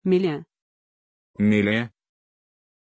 Pronunciation of Millie
pronunciation-millie-ru.mp3